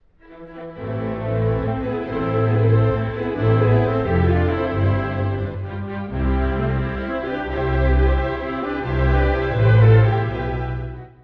↑古い録音のため聴きづらいかもしれません！（以下同様）
その後の主部も、序奏のテーマをもとに描かれます。
スコットランドの雲の広がる風景、その合間で時おりきらめく光が感じられます。